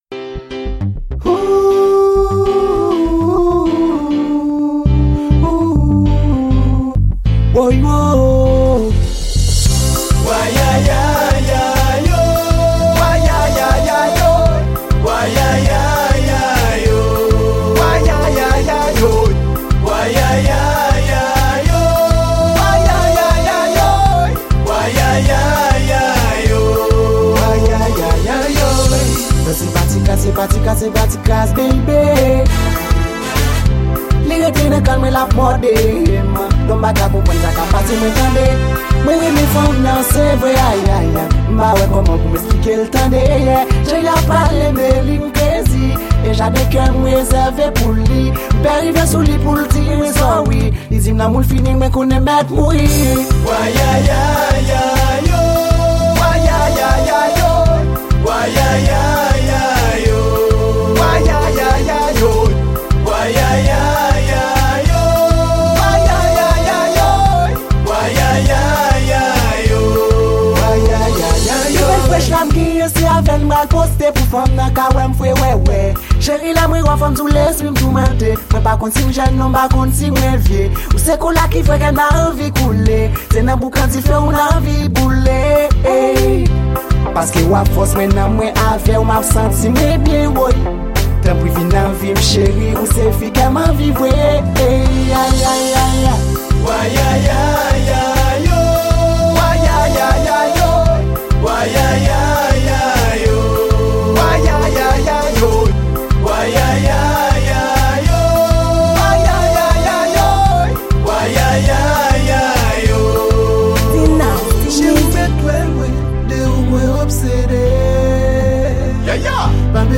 Genre: Dance Hall.